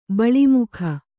♪ baḷi mukha